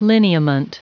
Prononciation du mot lineament en anglais (fichier audio)
Prononciation du mot : lineament